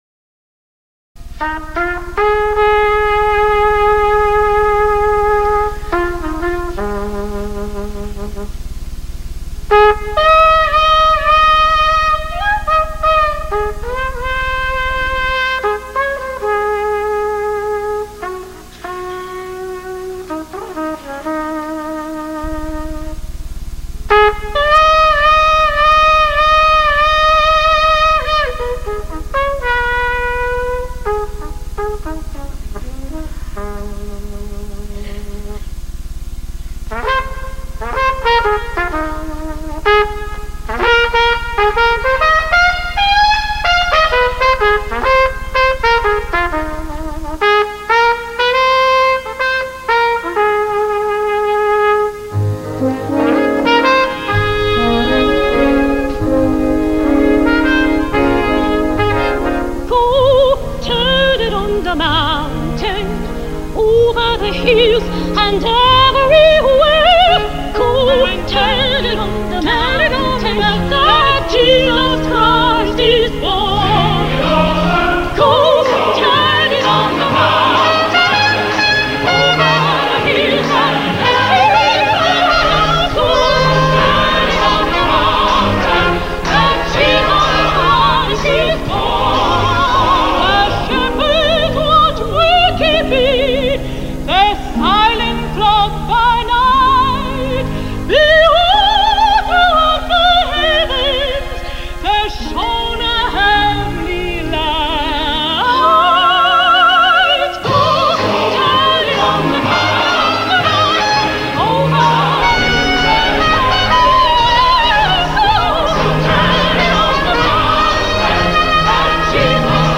Om in een vrolijke stemming te komen kun je even hier naar luisteren: Kathleen Battle, Frederica von Staden en Wynton Marsalis gaan helemaal uit hun dak met 'Go tell it on the mountain'.